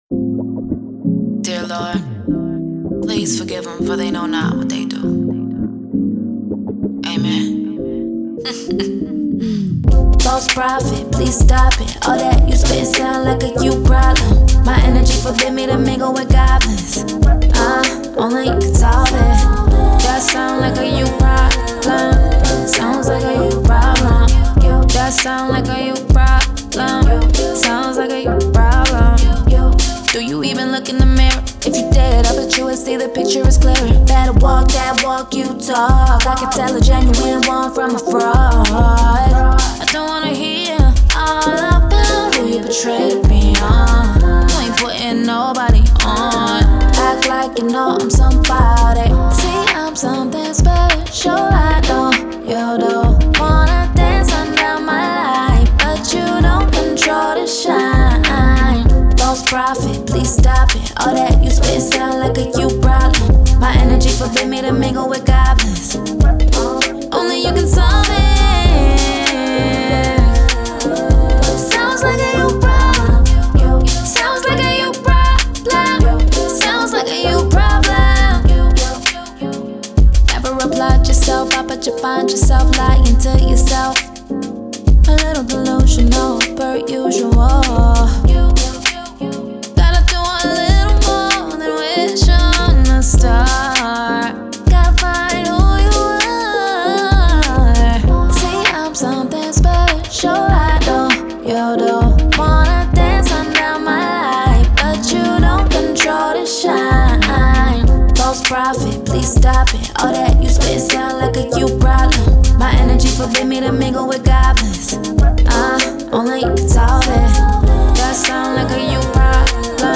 R&B
Ab Major